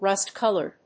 アクセントrúst‐còlored